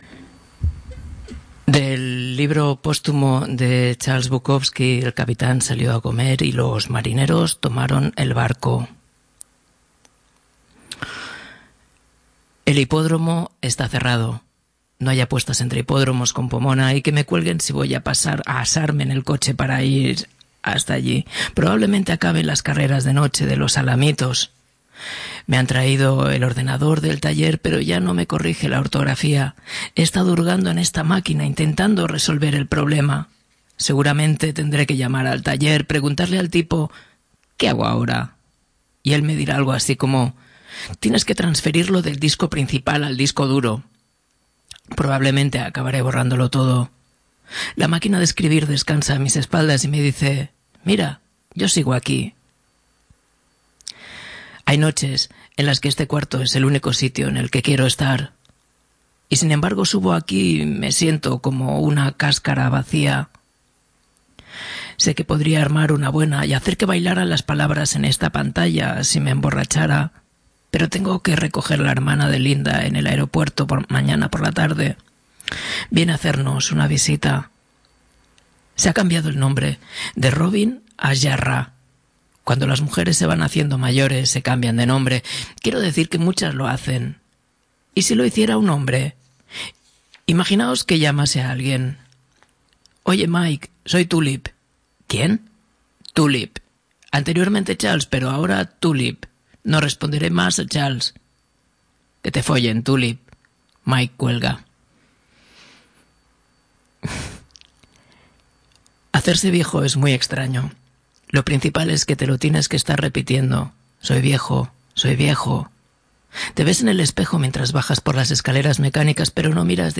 Leemos un demoledor relato de Ruth Toledano, su «Pesadilla animal», en la que nos imaginamos la llegada de una especie extraterrestre que adopta nuestros mismos modos de hacer. Retomamos la lectura de «La transparencia», de Antonio Rabinad, leemos algunas palabras extrañas en otras lenguas y rodeamos el programa con dos entradas del diario póstumo de Charles Bukowski, que nos hacían bastante gracia.